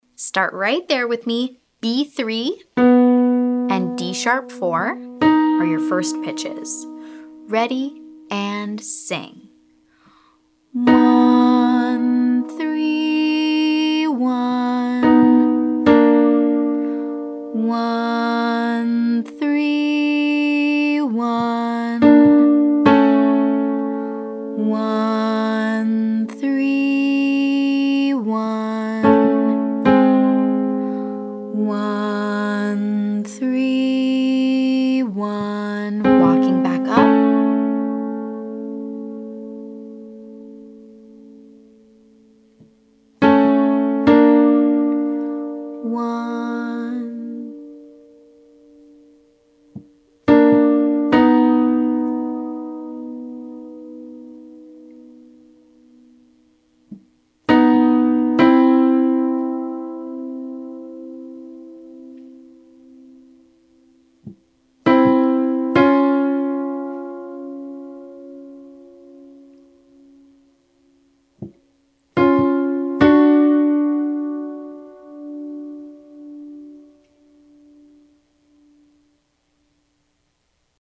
I’ll play the interval, but I won’t play individual pitches one at a time.
Exercise - 131: sing with piano playing interval (challenge)